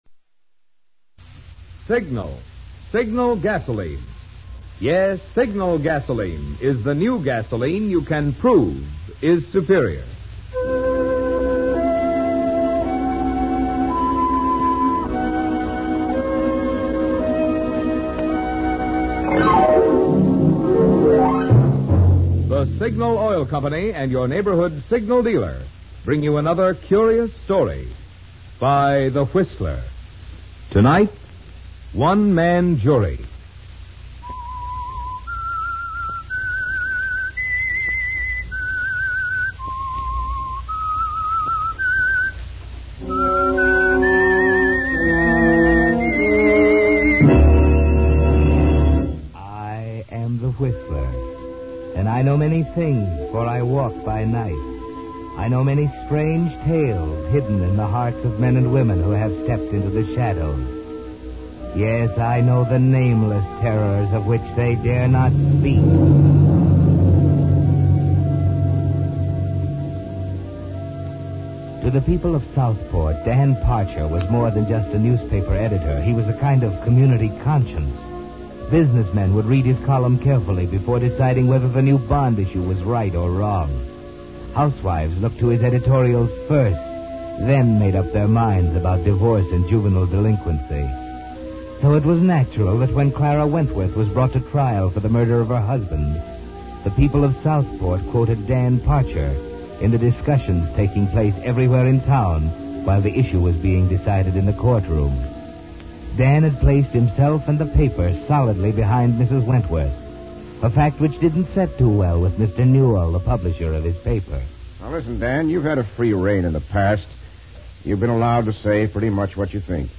Crusading newspaperman Dan Parcher backs a woman accused of murder in The Whistler: One Man Jury, a suspenseful classic old-time radio thriller.